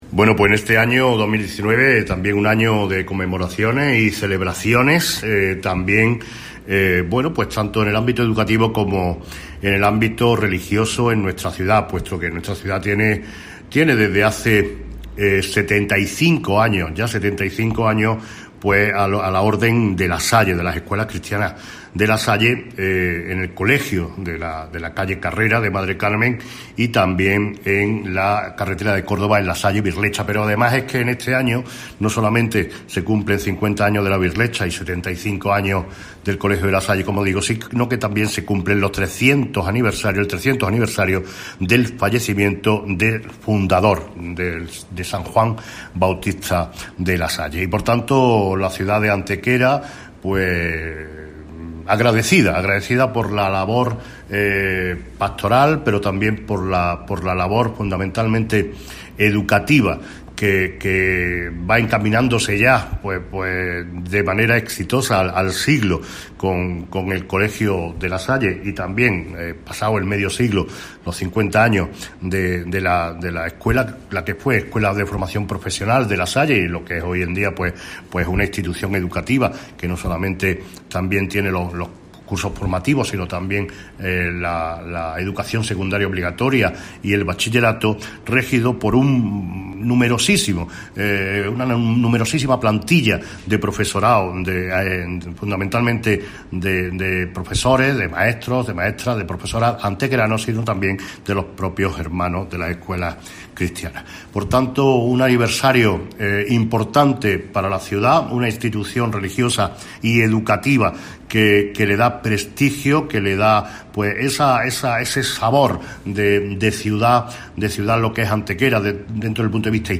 El alcalde de Antequera, Manolo Barón, confirma la concesión de la Medalla de Plata de la Jarra de Azucenas a los Hermanos de las Escuelas Cristianas de La Salle, institución implantada en casi un centenar de países que se dedica a la educación y que en el caso de nuestra ciudad cuenta con dos centros: el Colegio San Francisco Javier La Salle, ubicado en la carrera de Madre Carmen, y La Salle-Virlecha, situado en la carretera de Córdoba.
Cortes de voz